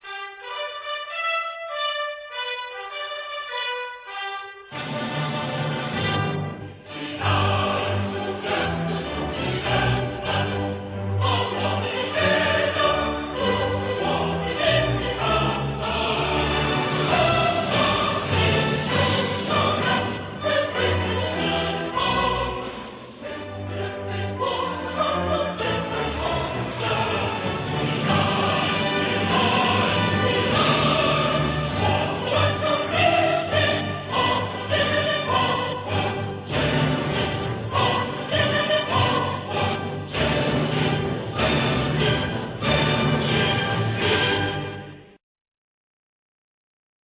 歌唱版RA